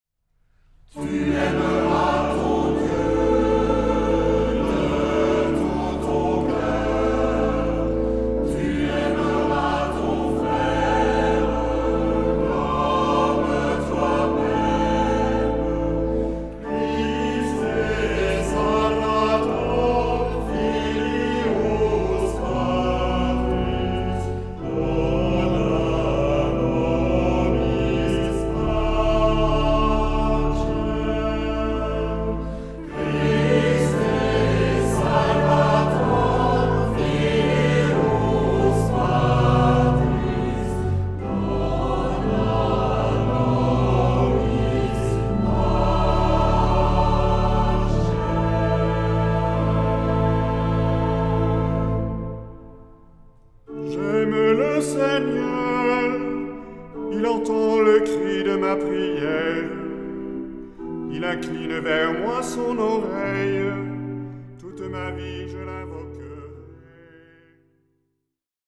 Genre-Style-Form: Sacred ; troparium ; Psalm
Mood of the piece: collected
Type of Choir: SATB  (4 mixed voices )
Instruments: Organ (1) ; Melody instrument (1)
Tonality: D mixolydian